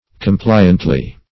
compliantly - definition of compliantly - synonyms, pronunciation, spelling from Free Dictionary Search Result for " compliantly" : The Collaborative International Dictionary of English v.0.48: Compliantly \Com*pli"ant*ly\, adv. In a compliant manner.